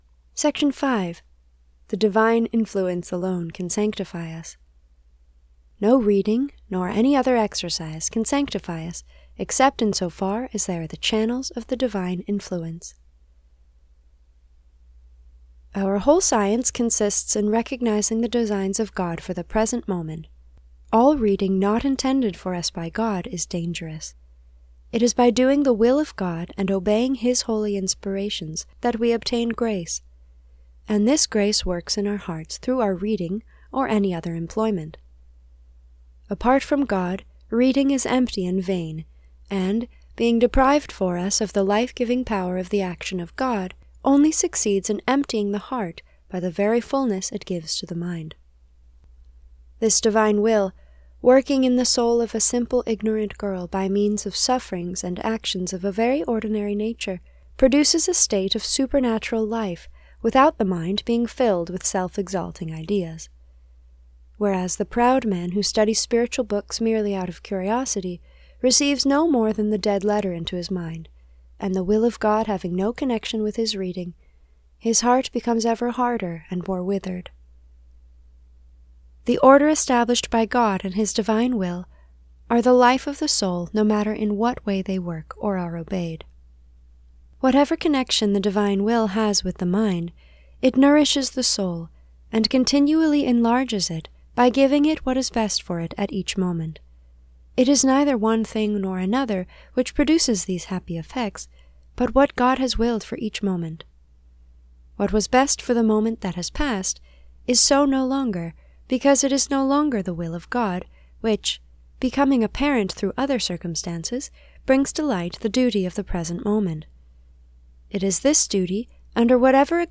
This is a reading from the spiritual classic Abandonment to Divine Providence by Jean Pierre de Caussade (1675 - 1751).